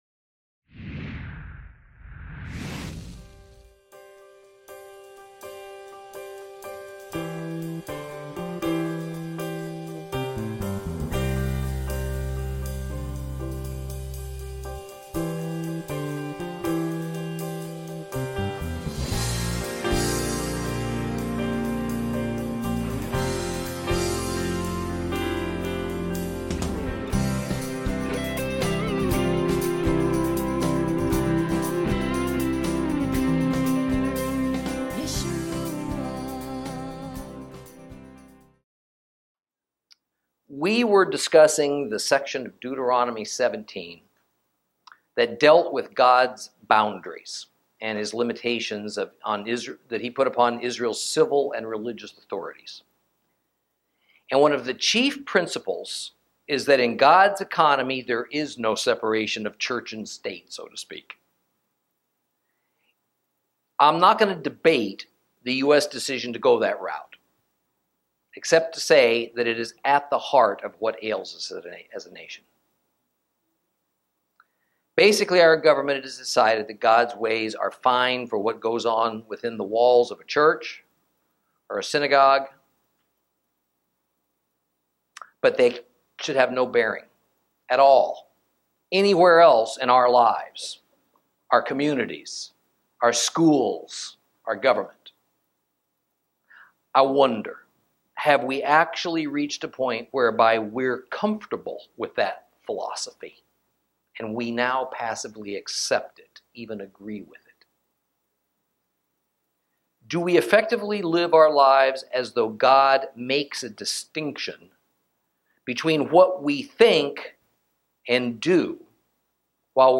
Lesson 23 Ch17 Ch18 - Torah Class